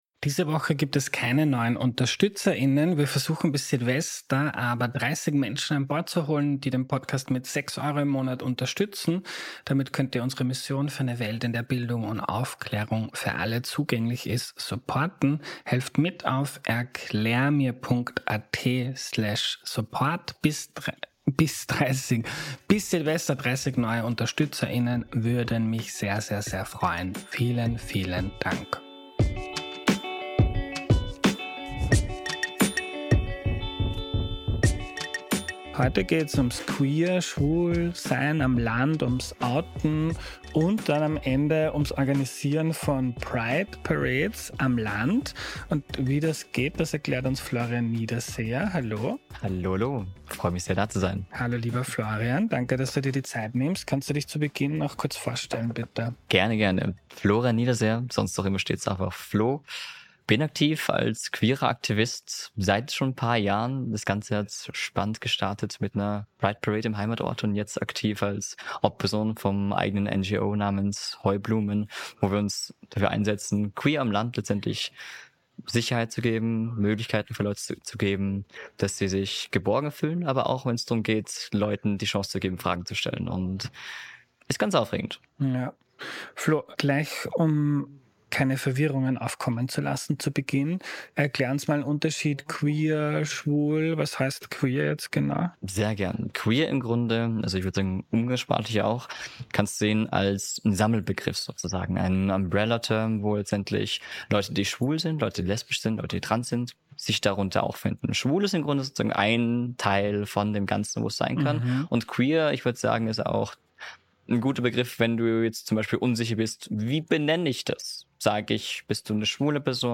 Ein Gespräch, das Mut macht und inspiriert.